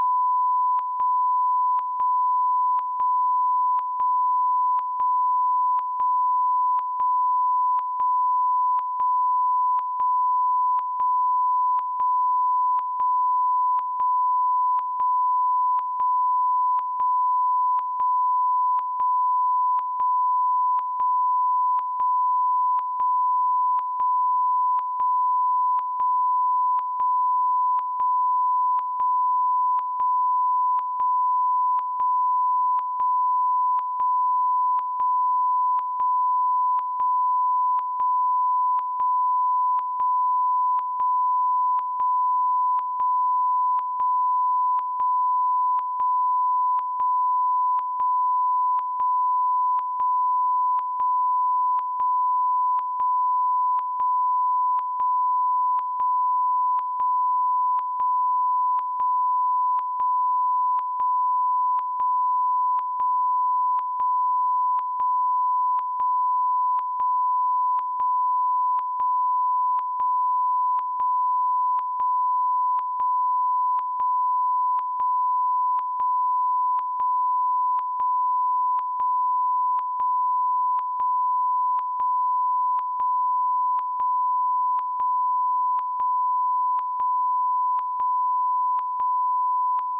TONS